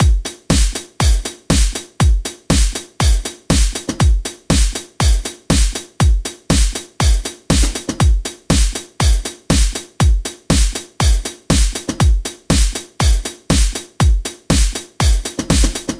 ringmoddelaydrumsloop
描述：drum loop plus pretty cool ring mod and tape delay
标签： 120bpm beats delay drums electronic loop processed ringmod
声道立体声